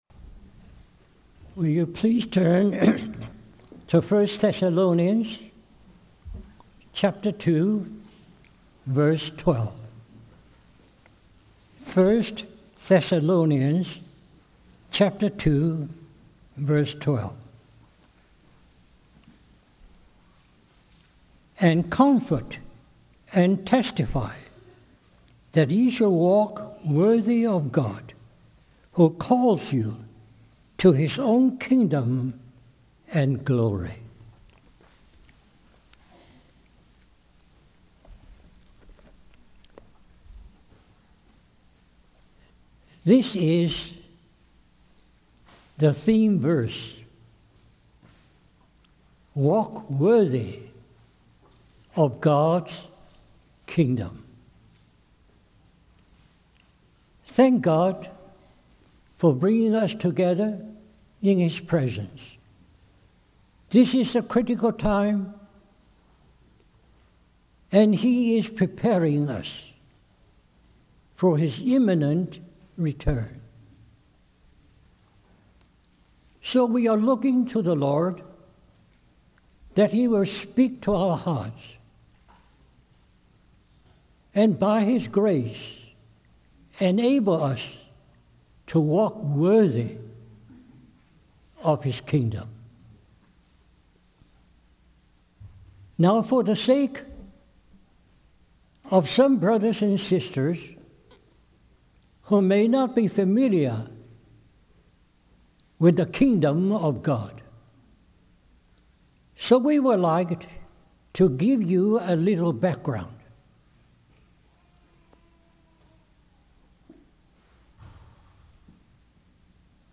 A collection of Christ focused messages published by the Christian Testimony Ministry in Richmond, VA.
2011 Harvey Cedars Conference Stream or download mp3 Recommended Walk Worthy of the Kingdom of God